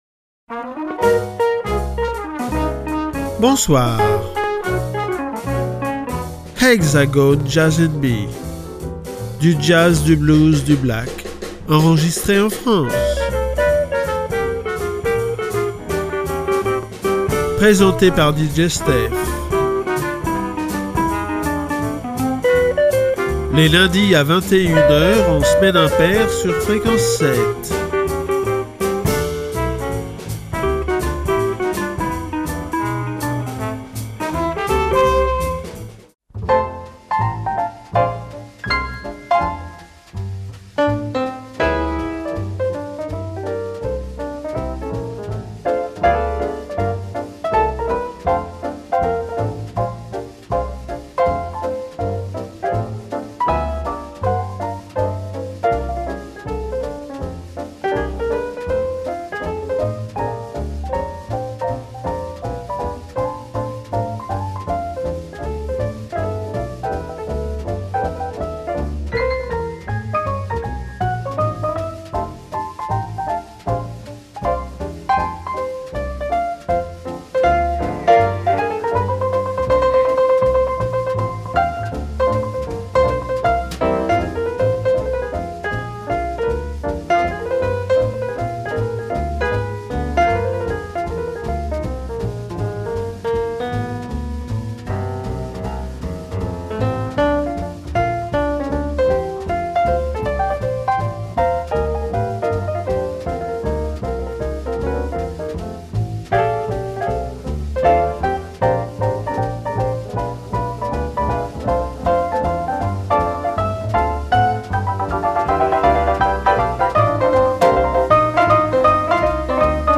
Jazz Français ou French Jazz.